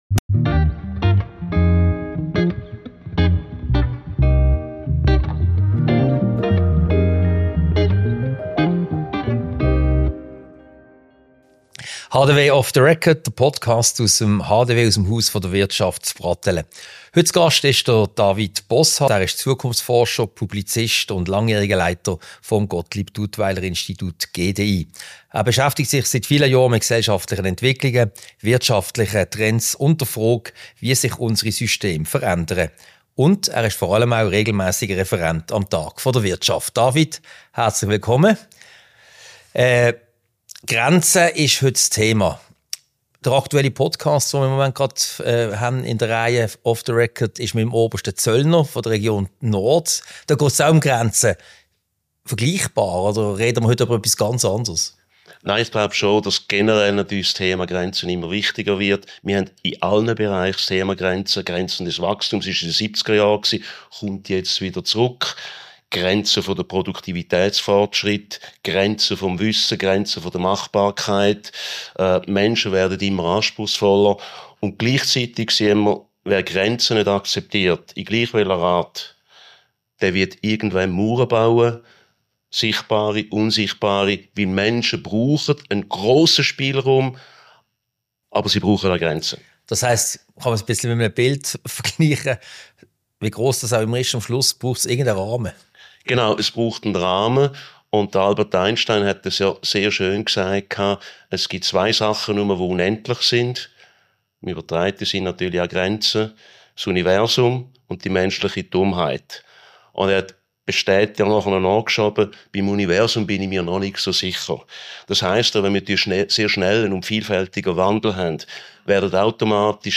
Ein Gespräch über die diversen Arten von Grenzen, über Freiheit und den moralischen Kompass....